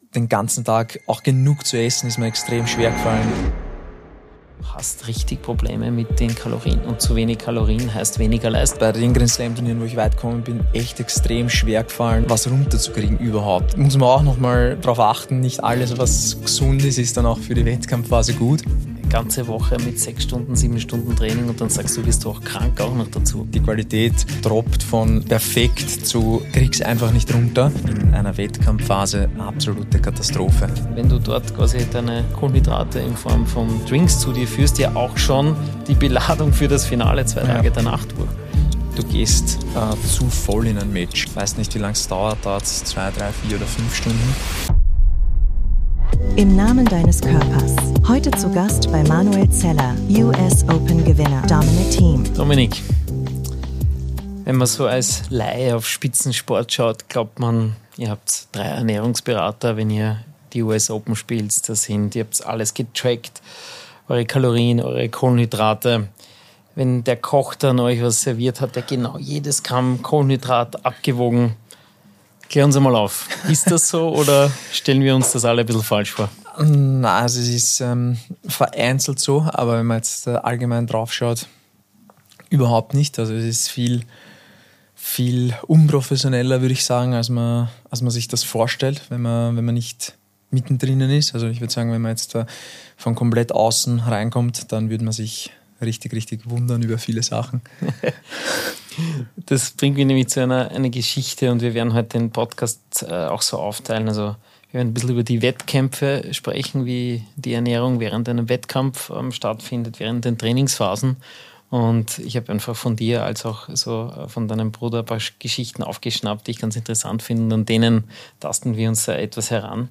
Warum vieles weniger professionell ist, als man denkt, wo Strukturen fehlen und was Athleten im Alltag tatsächlich essen. Ein ehrliches Gespräch über Leistung, Routinen, Fehleinschätzungen und die Realität hinter dem Spitzensport.